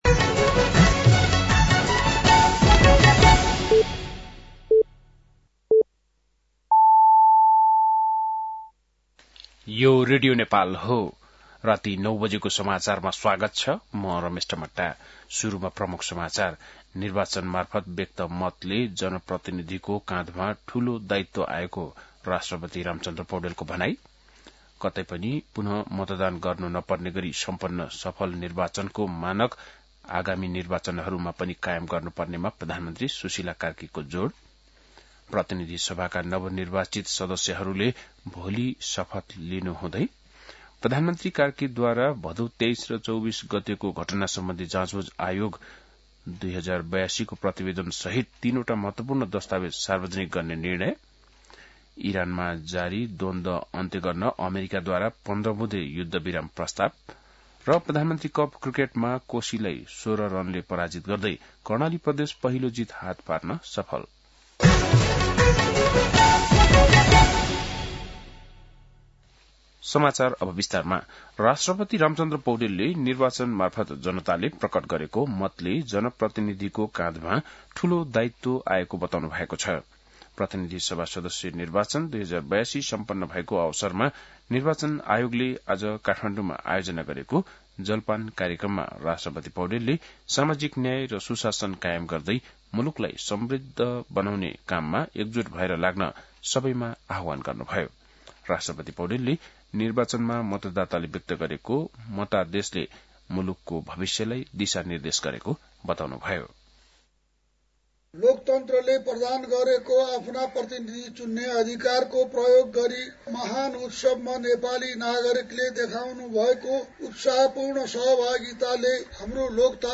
बेलुकी ९ बजेको नेपाली समाचार : ११ चैत , २०८२